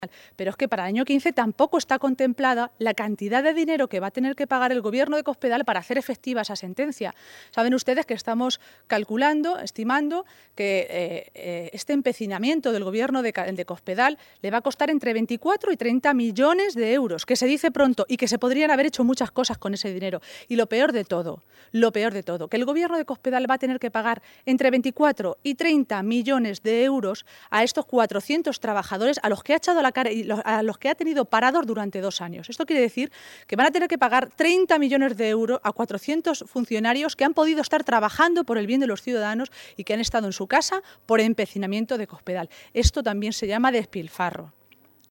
Maestre se pronunciaba de esta manera esta tarde, en Manzanares, en el Castillo de Pilas Bonas, minutos antes de que comenzara la reunión de la ejecutiva regional socialista.
Cortes de audio de la rueda de prensa